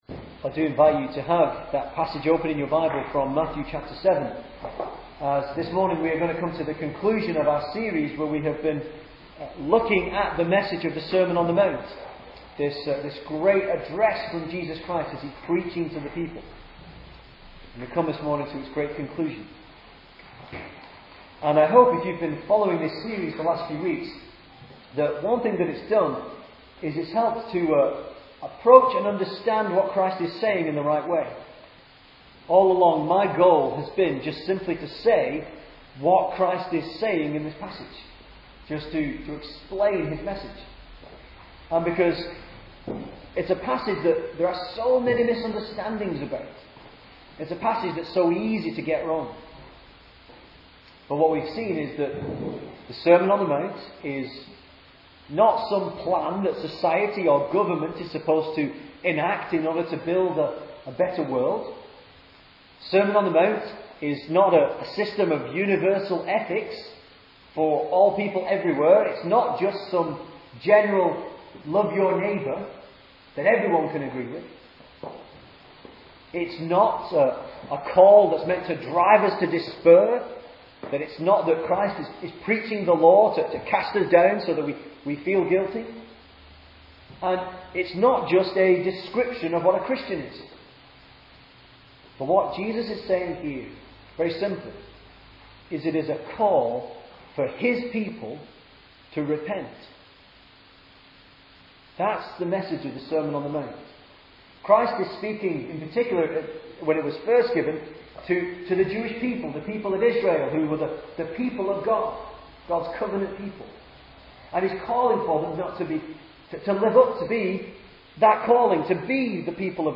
2010 Service Type: Sunday Morning Speaker